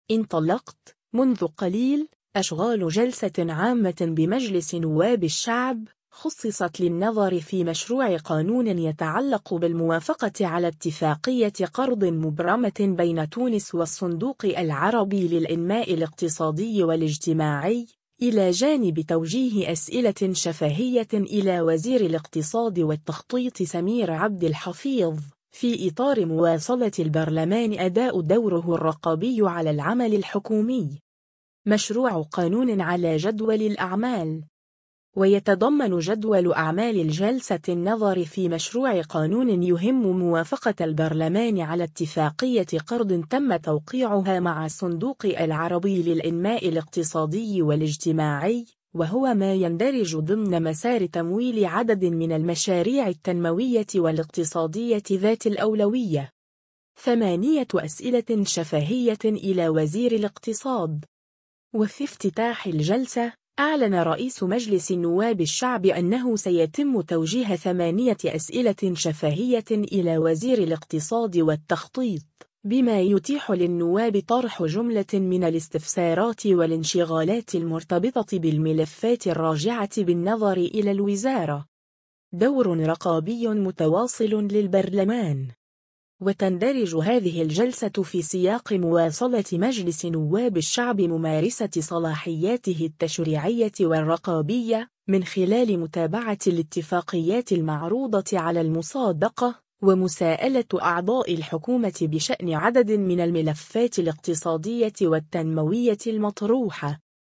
انطلقت، منذ قليل، أشغال جلسة عامة بمجلس نواب الشعب، خُصّصت للنظر في مشروع قانون يتعلق بالموافقة على اتفاقية قرض مبرمة بين تونس والصندوق العربي للإنماء الاقتصادي والاجتماعي، إلى جانب توجيه أسئلة شفاهية إلى وزير الاقتصاد والتخطيط سمير عبد الحفيظ، في إطار مواصلة البرلمان أداء دوره الرقابي على العمل الحكومي.
وفي افتتاح الجلسة، أعلن رئيس مجلس نواب الشعب أنه سيتم توجيه 8 أسئلة شفاهية إلى وزير الاقتصاد والتخطيط، بما يتيح للنواب طرح جملة من الاستفسارات والانشغالات المرتبطة بالملفات الراجعة بالنظر إلى الوزارة.